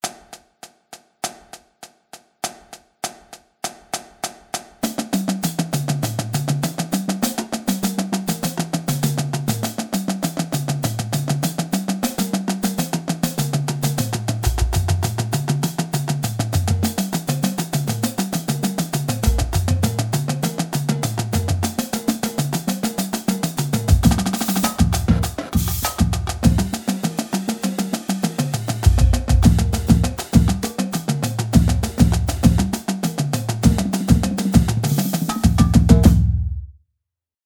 Stick Control 2 - 100.mp3